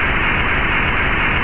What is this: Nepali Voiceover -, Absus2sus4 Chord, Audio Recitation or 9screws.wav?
9screws.wav